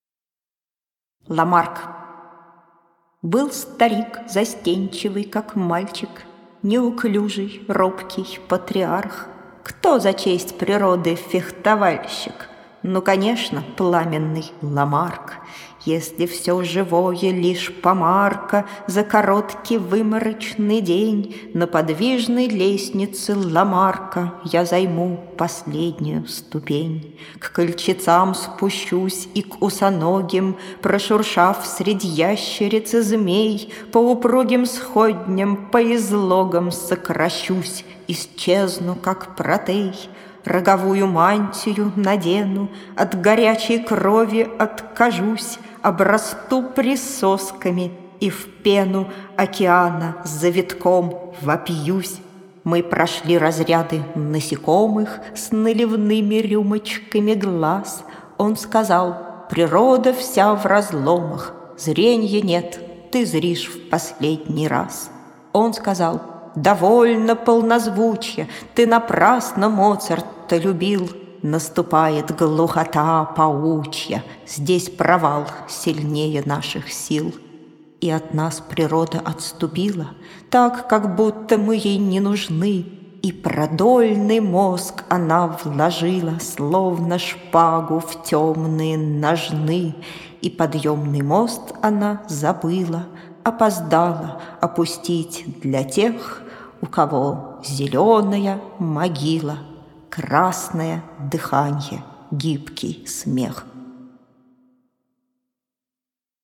1. «Осип Мандельштам – Ламарк (чит. Вера Павлова)» /